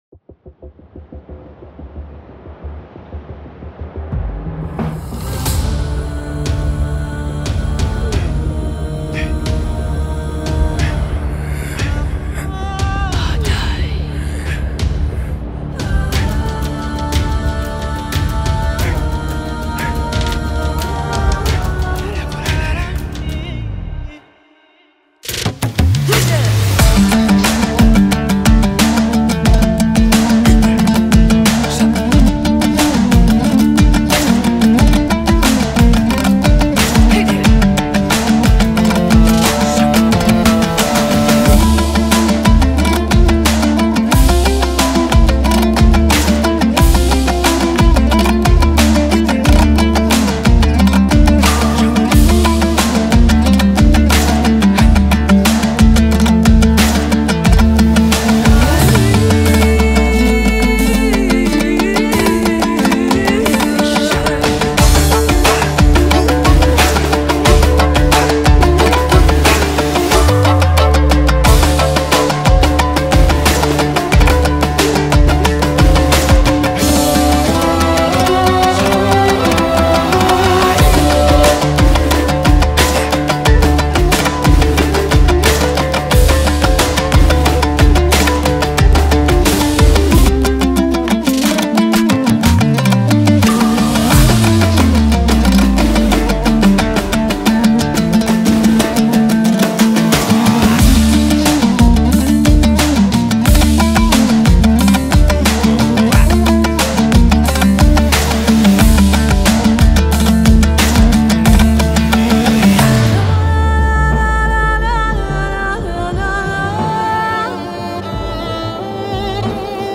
это энергичная песня в жанре поп